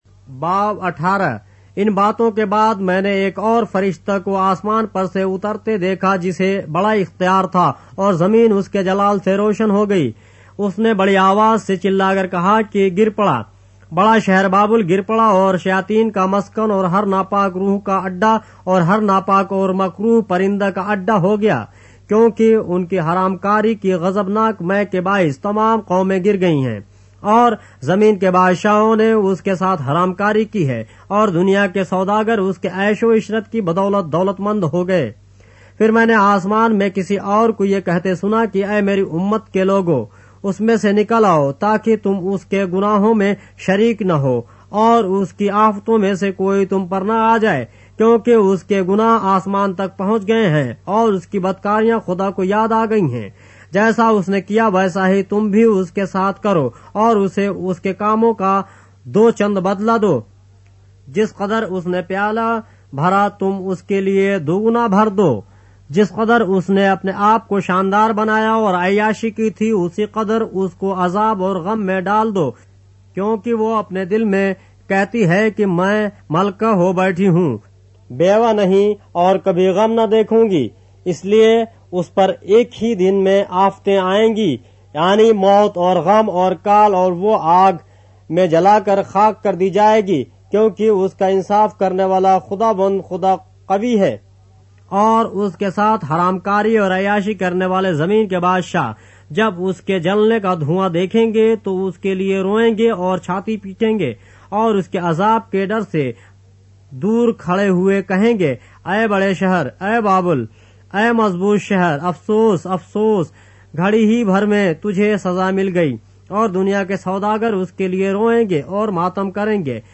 اردو بائبل کے باب - آڈیو روایت کے ساتھ - Revelation, chapter 18 of the Holy Bible in Urdu